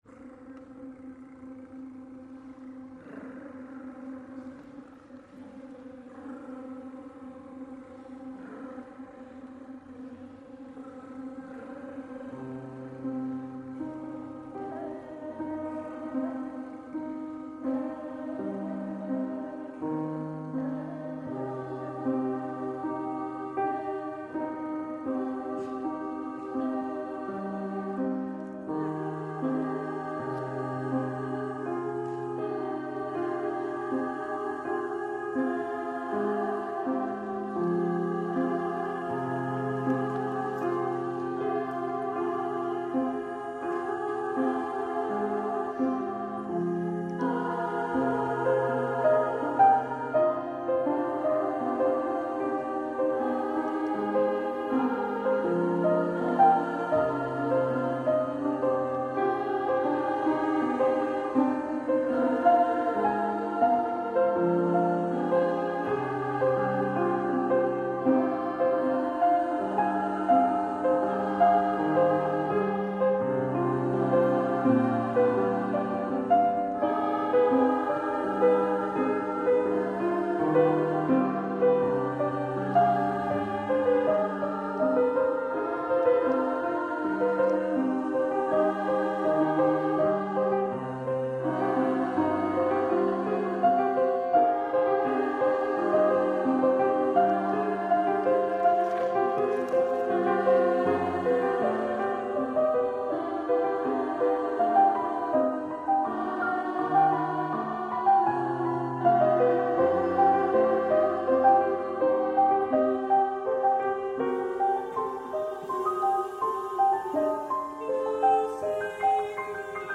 For Women's Choir